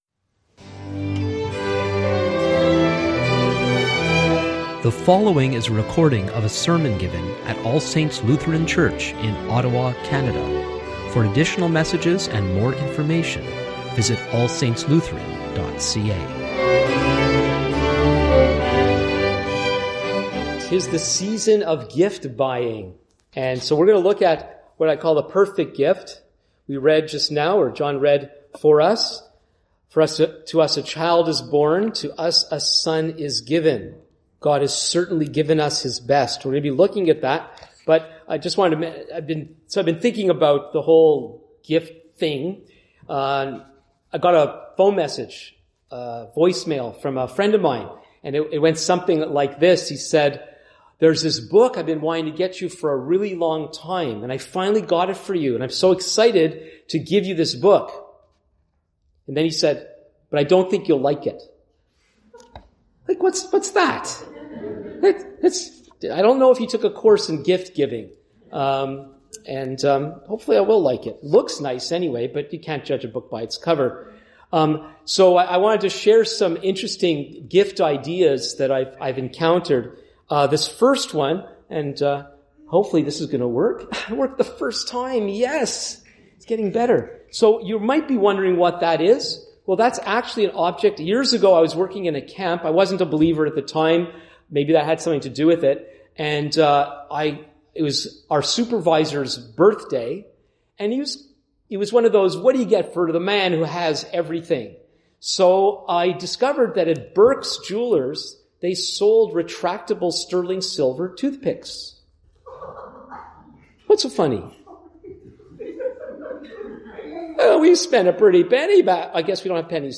Sermons | All Saints Lutheran Church